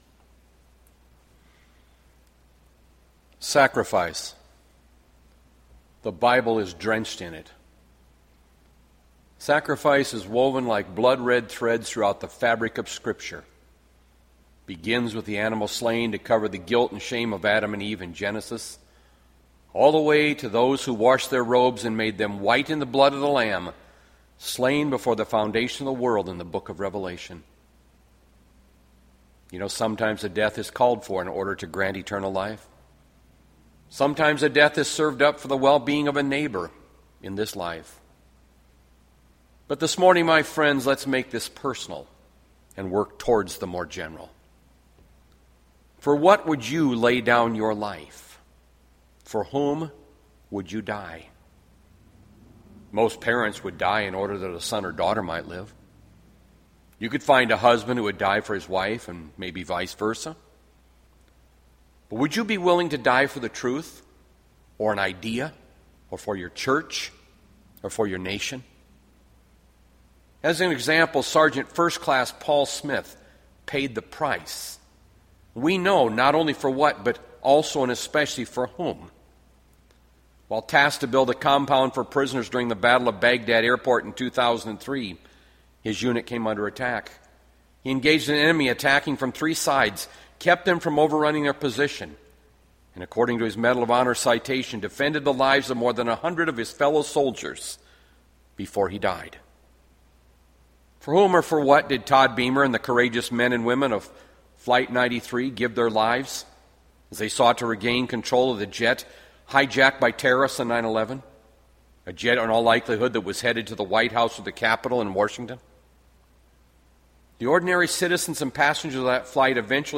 Jun 14, 2020  SERMON ARCHIVE